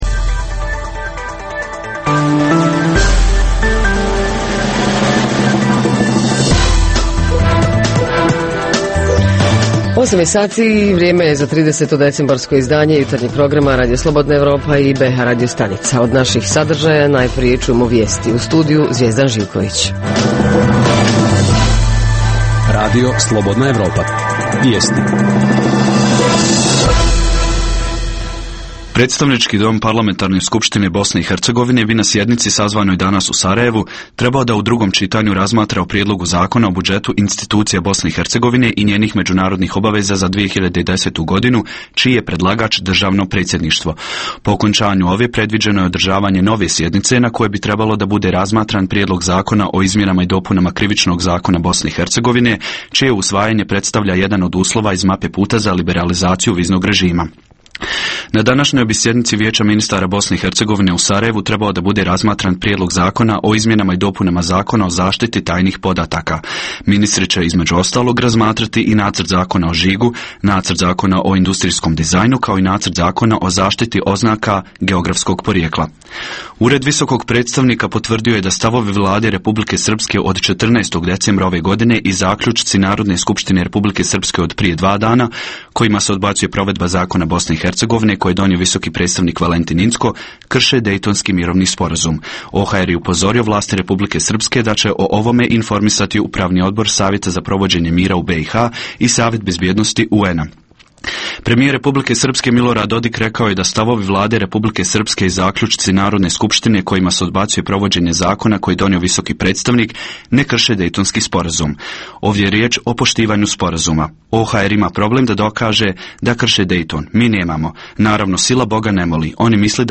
Da li su kadrovski i opremom spremne gradske službe za novogodišnju noć ( vatrogasci, hitna, policija i druge) Savjeti i upozorenja građanima… Reporteri iz cijele BiH javljaju o najaktuelnijim događajima u njihovim sredinama.